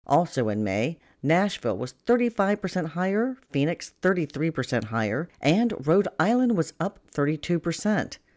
AP correspondent reports on Housing Evictions Rising.